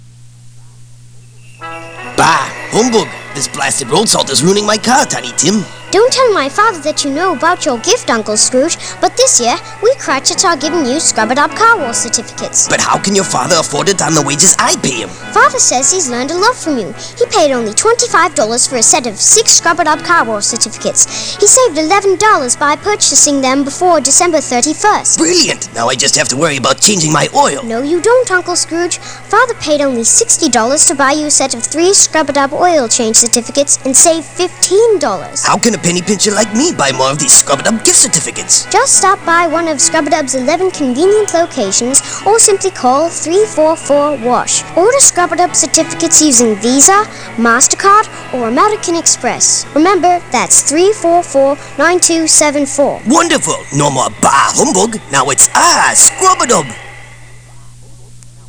The literature was supported by a radio ad that I wrote and directed using the same characters from �A Christmas Carol.��